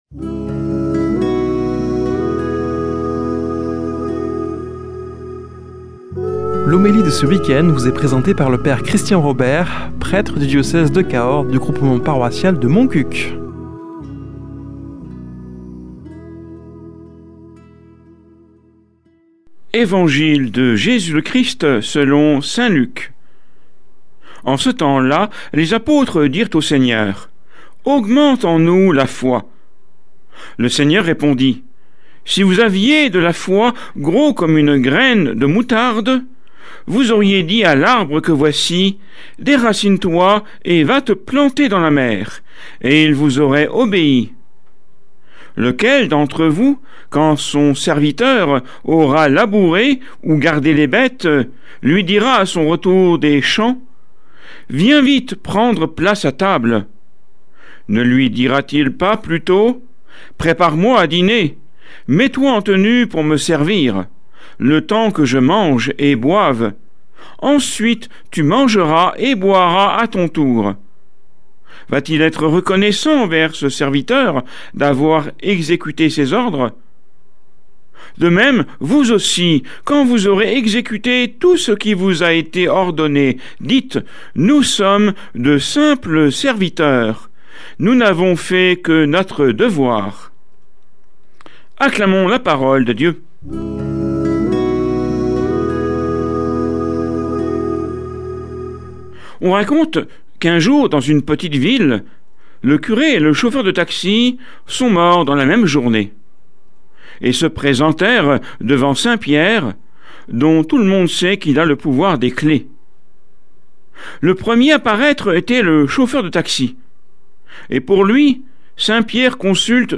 Homélie du 04 oct.